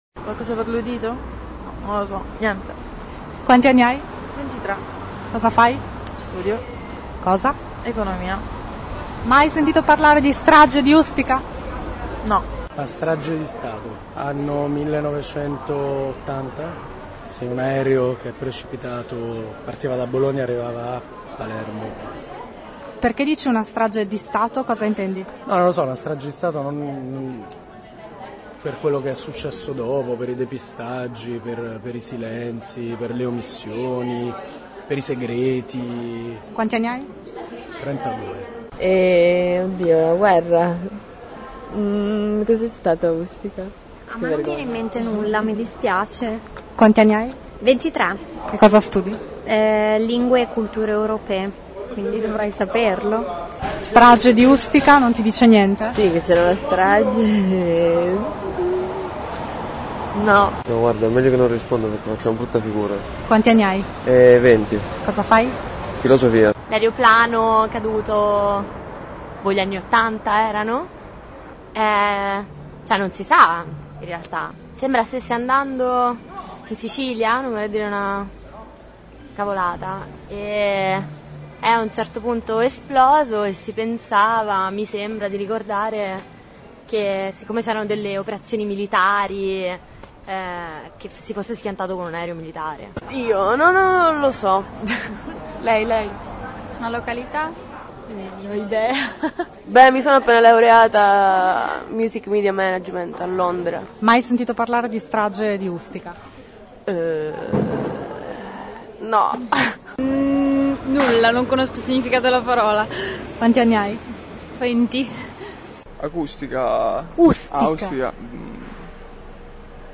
Sono interviste prese a caso, quindi abbiamo fatto la controprova: siamo tornati in zona universitaria a chiedere alle studentesse e agli studenti dell’Alma Mater cosa associassero alla parole “Ustica”.
E gli operai erano informati: quasi tutti hanno risposto in modo circostanziato.
voci-ustica-giovani_voci-operai_radio-version.mp3